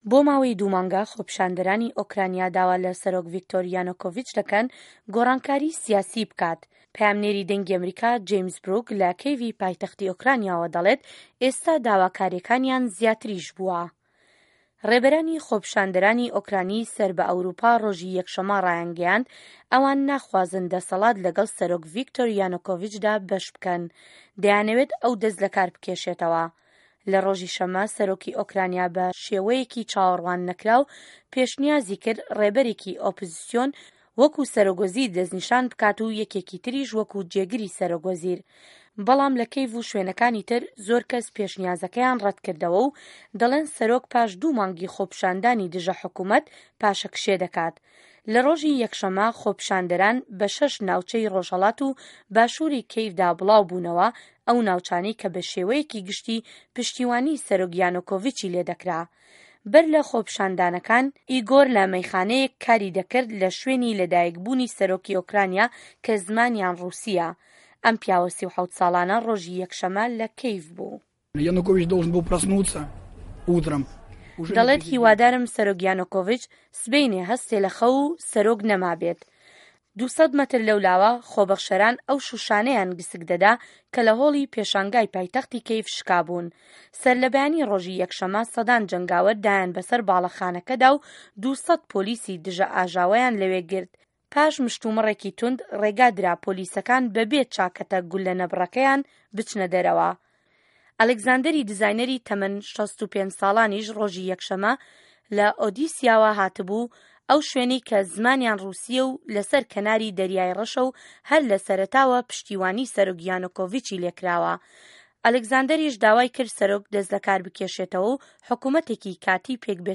زانیاری زیاتر له‌م ڕاپۆرته‌دایه‌.